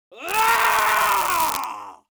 Screams Male 05
Screams Male 05.wav